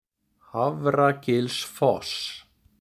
Hafragilsfoss (Icelandic pronunciation: [ˈhavraˌcɪlsˌfɔsː]
Hafragilsfoss_pronunciation.ogg.mp3